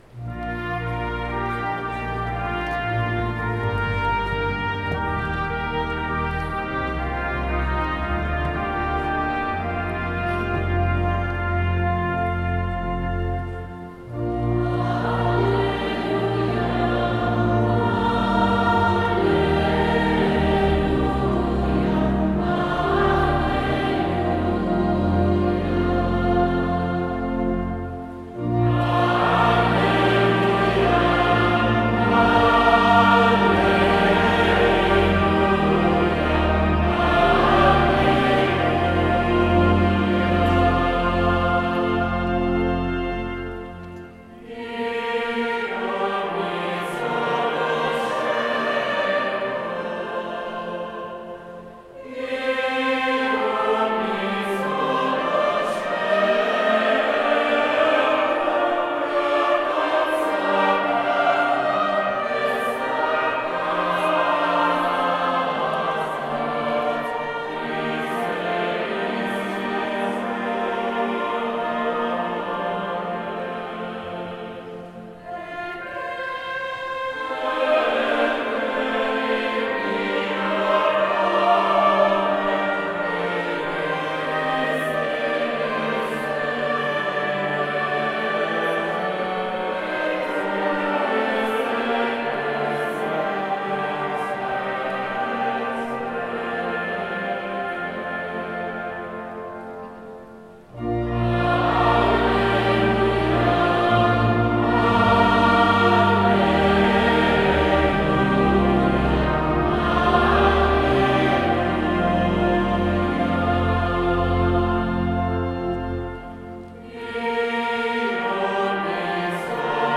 Gallery >> Audio >> Audio2017 >> 750 Dedicazione Cattedrale >> 06-Alleluia-VersVangelo 750Dedicazione 26Apr2017